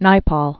(nīpôl), Sir.